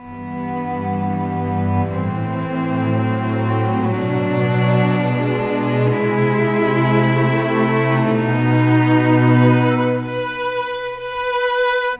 Cinematic 27 Strings 02.wav